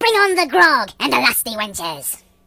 project_files/HedgewarsMobile/Audio/Sounds/voices/Pirate/Flawless.ogg